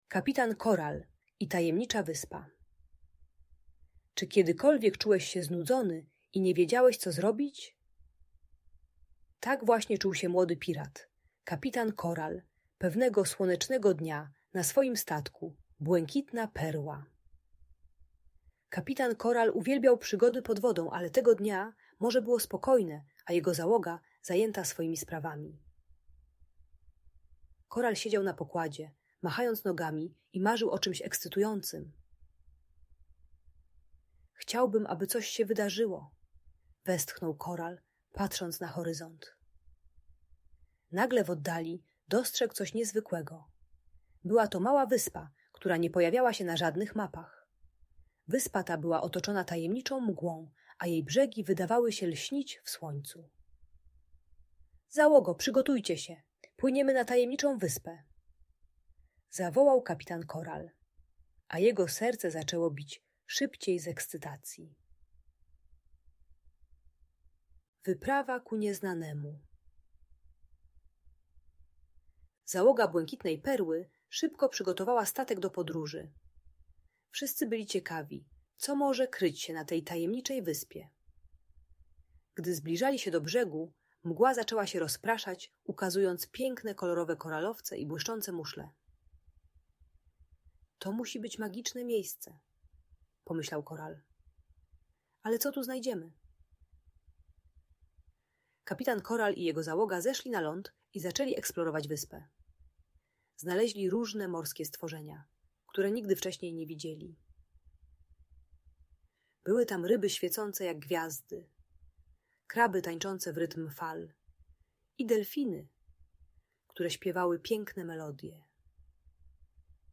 Kapitan Koral i Tajemnicza Wyspa - Audiobajka dla dzieci